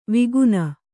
♪ viguna